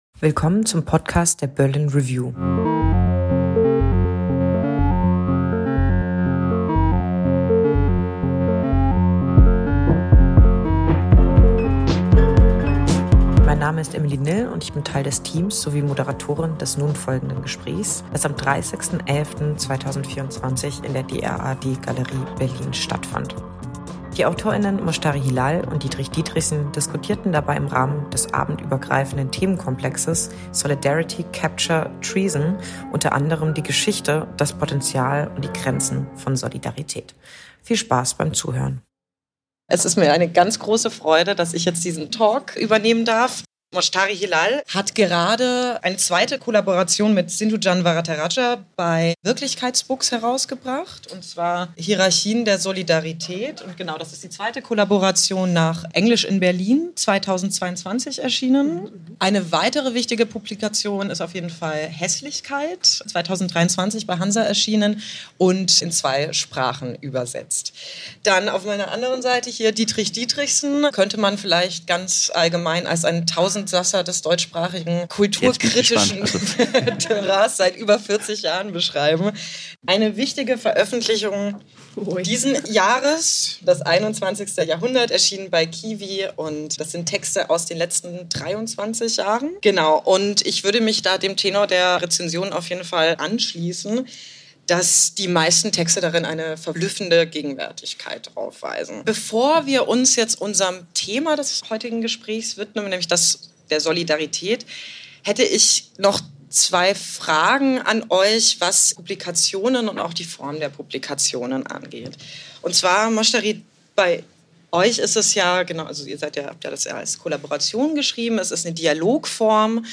Moshtari Hilal und Diedrich Diederichsen im Gespräch über Solidarität, Verrat und Vereinnahmung